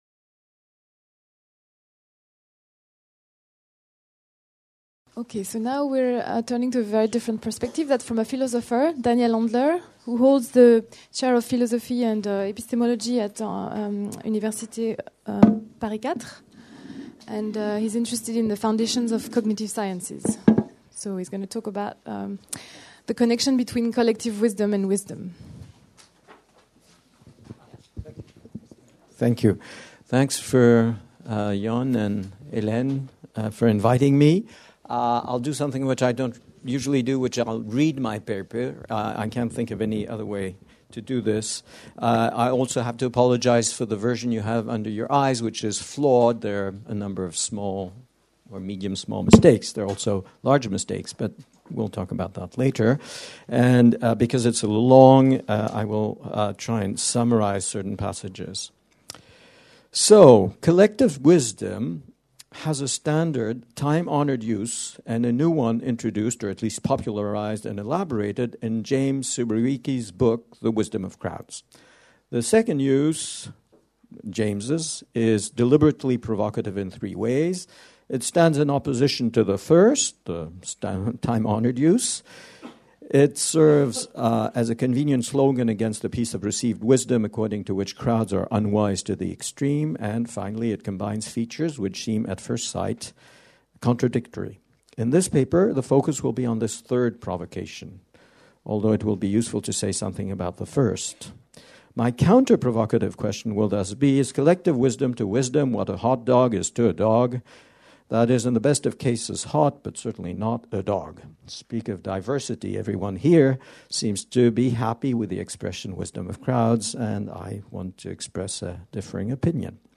La sagesse collective : principes et mécanismes Colloque des 22-23 mai 2008, organisé par l'Institut du Monde Contemporain du Collège de France, sous la direction du Professeur Jon Elster.